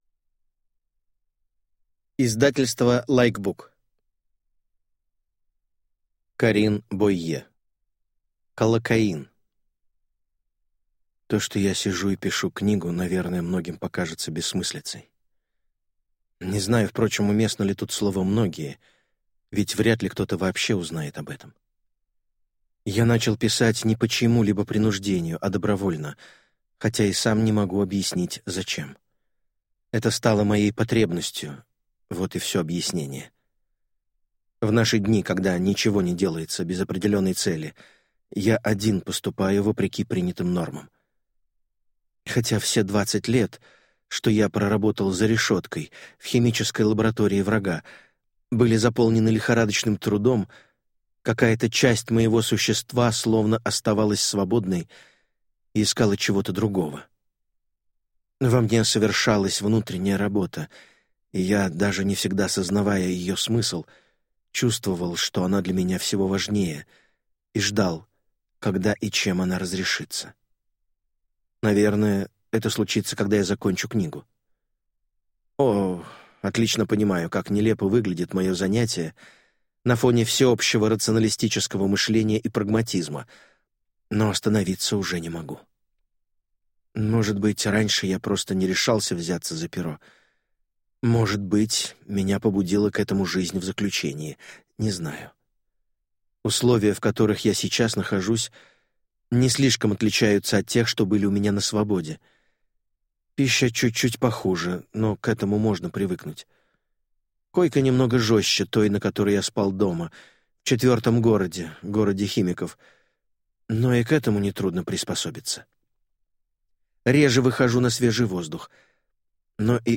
Аудиокнига Каллокаин | Библиотека аудиокниг